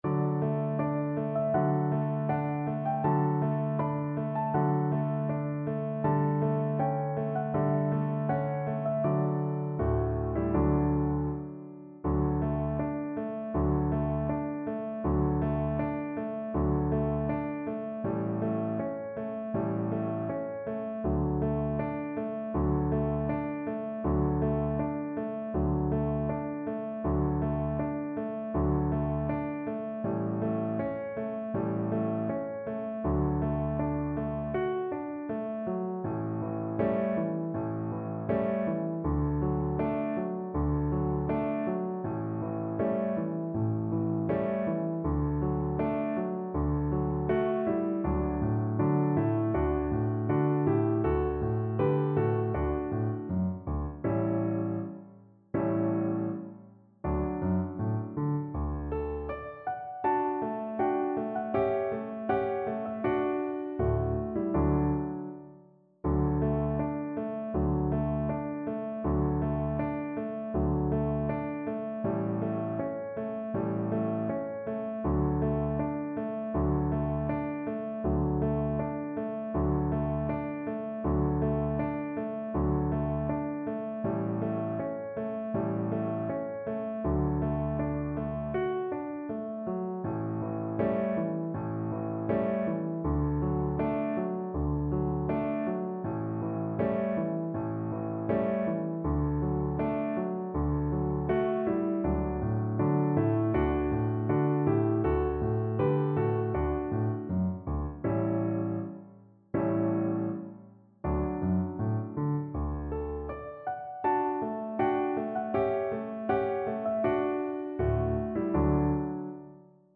4/4 (View more 4/4 Music)
Andante